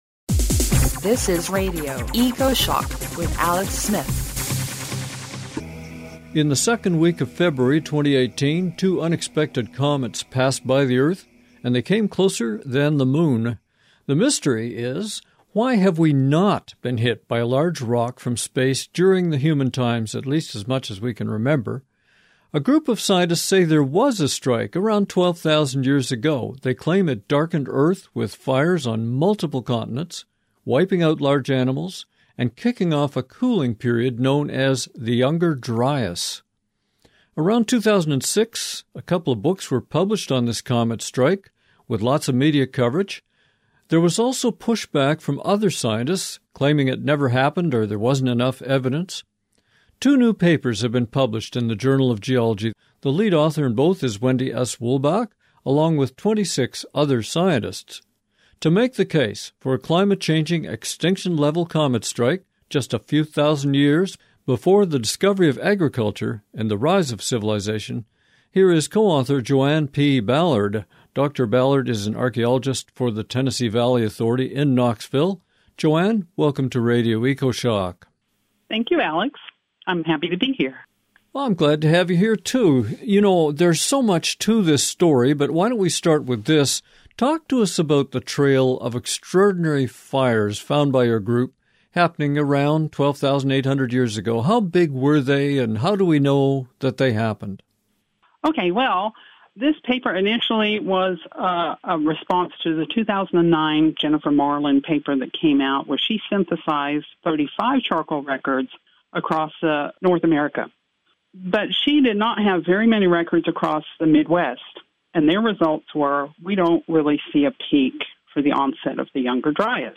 DOWNLOAD OUR GUEST INTERVIEWS FOR FREE